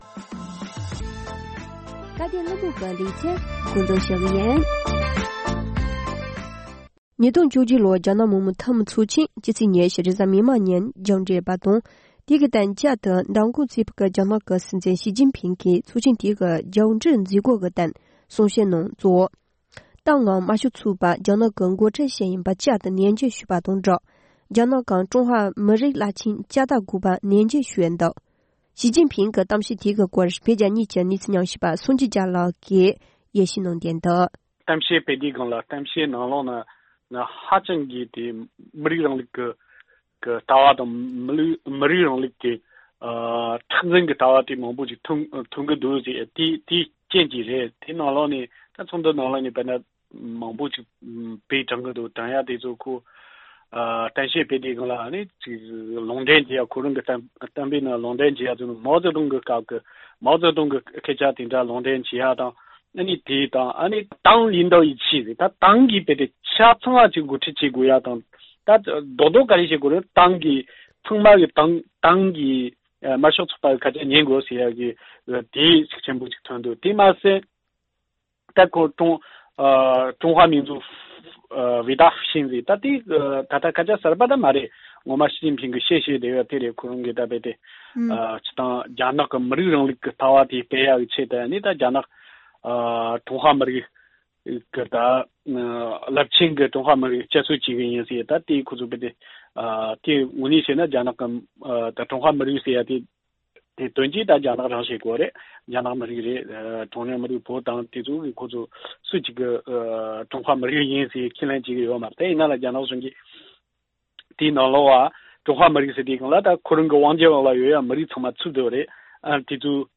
༄༅། །ཐེངས་འདིའི་སྐབས་དོན་གླེང་མོལ་གྱི་ལེ་ཚན་ནང་།